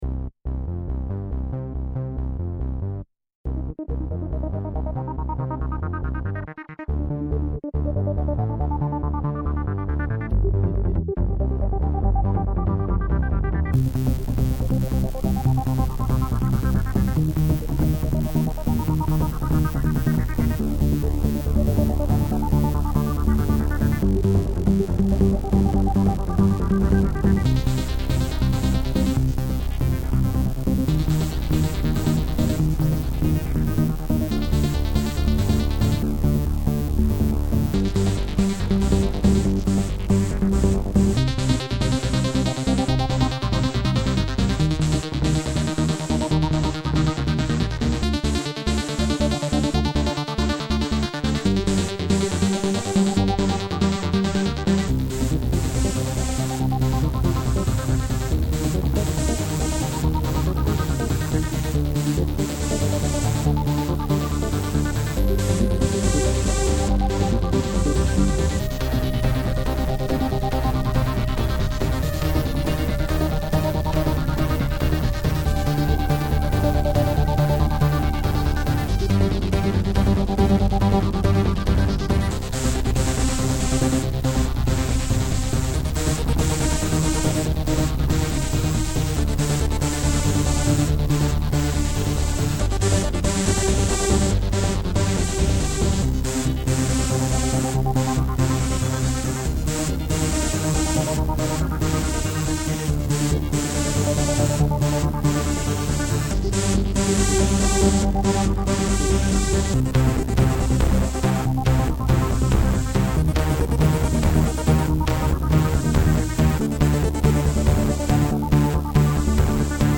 techno-muziekje
zonder drum.